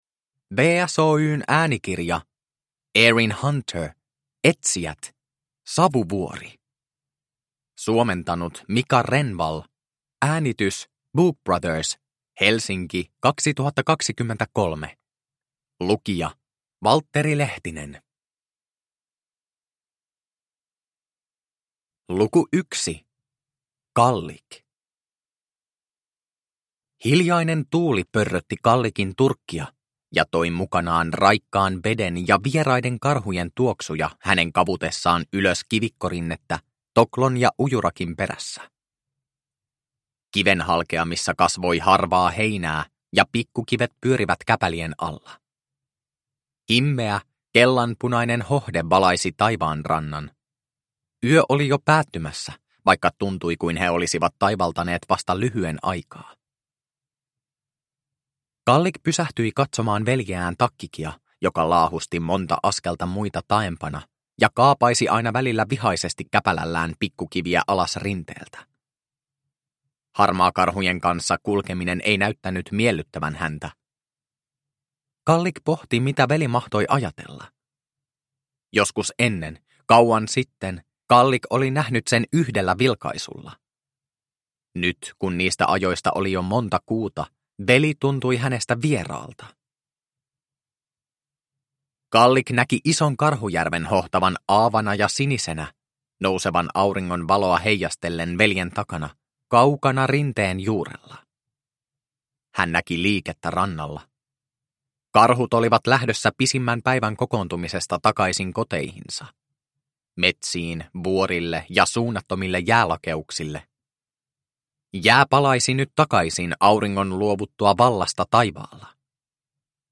Etsijät: Savuvuori – Ljudbok – Laddas ner